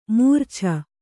♪ mūrchana